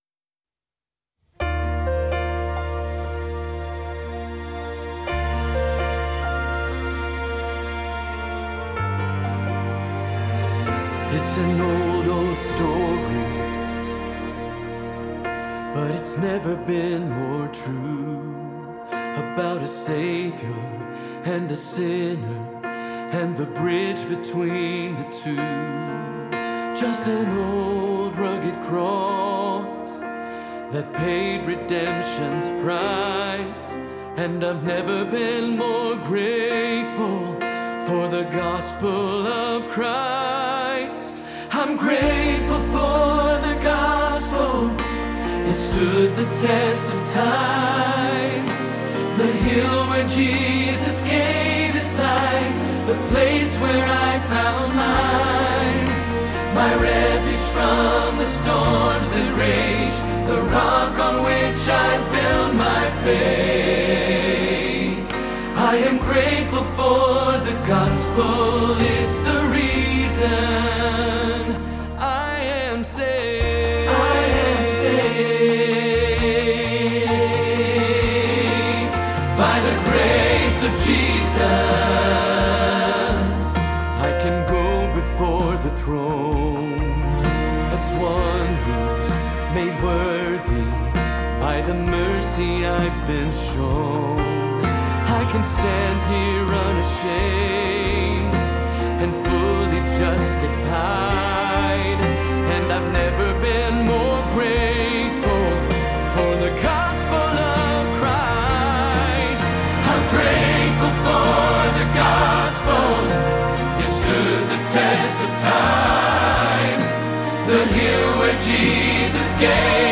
05-Grateful-for-the-Gospel-Bass-Rehearsal-Track.mp3